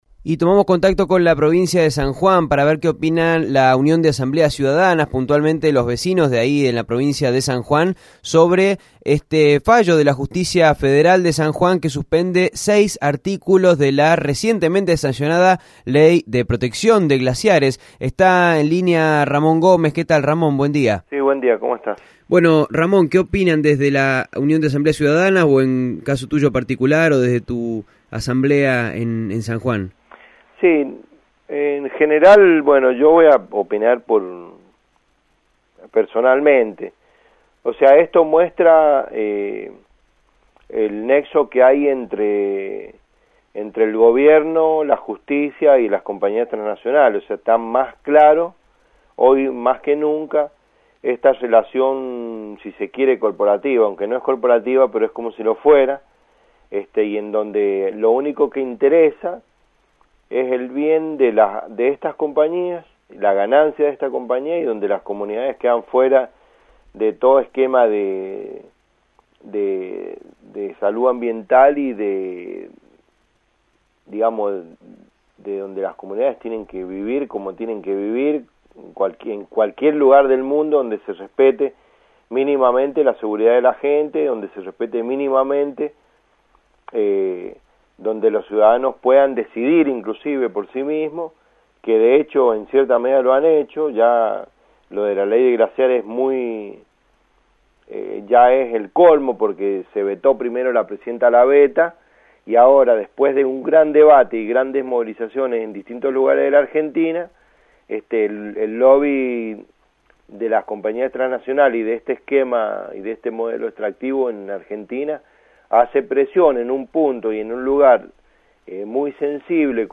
Ley de Protección de Glaciares, entrevista